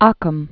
(ŏkəm), William of 1285?-1349?